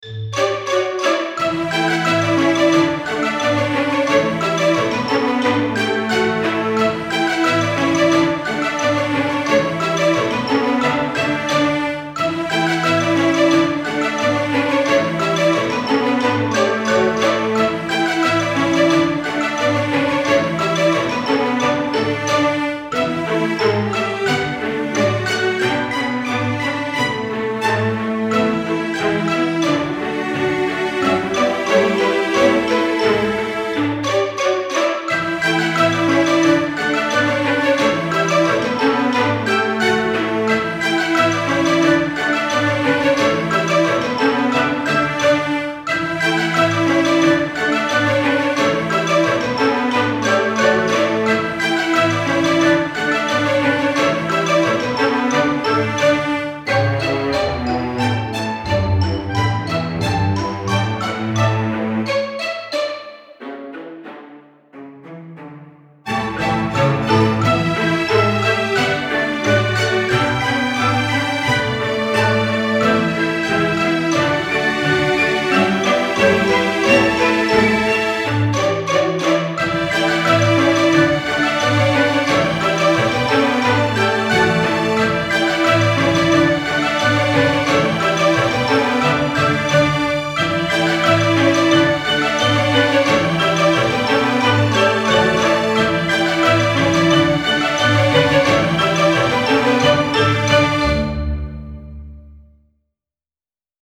Style Style Oldies, Orchestral, Soundtrack
Mood Mood Bouncy, Bright
Featured Featured Strings, Xylophone
BPM BPM 89